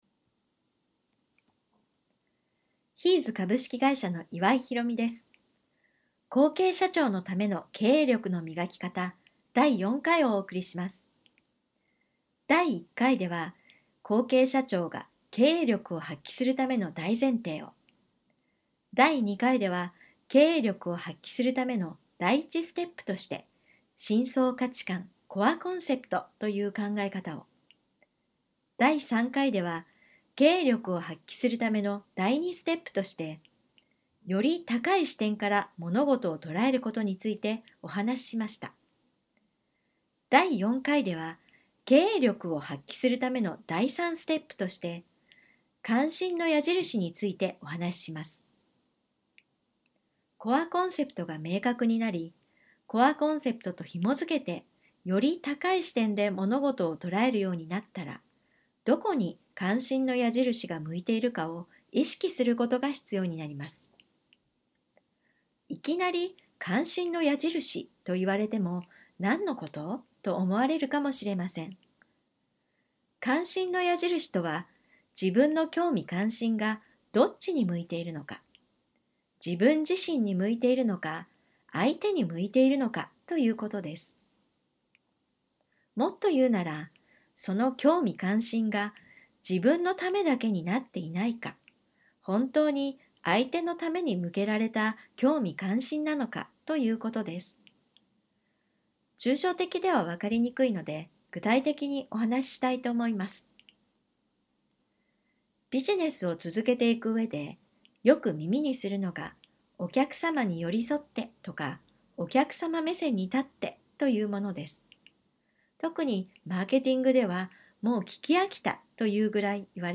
音声講座「後継社長のための経営力の磨き方」（その４）をリリースしました。